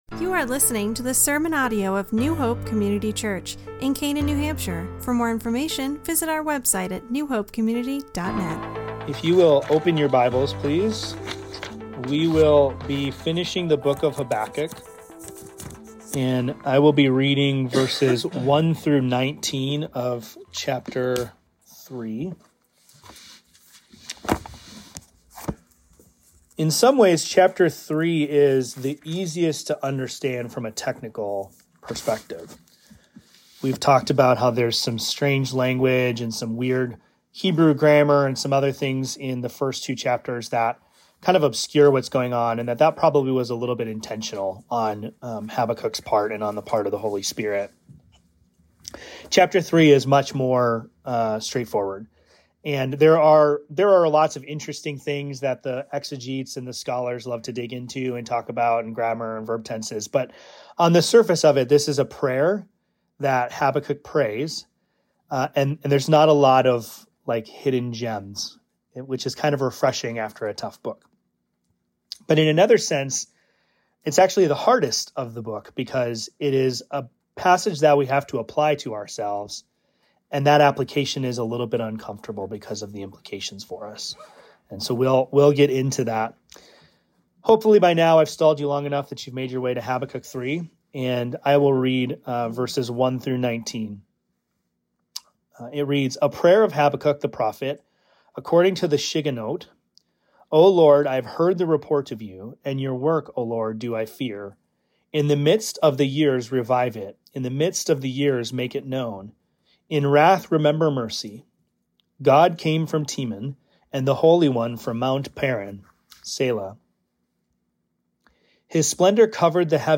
This sermon focuses on Habakkuk 3:1–19, the prophet’s prayer and response to God’s revelation.